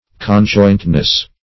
Conjointness \Con*joint"ness\, n.
conjointness.mp3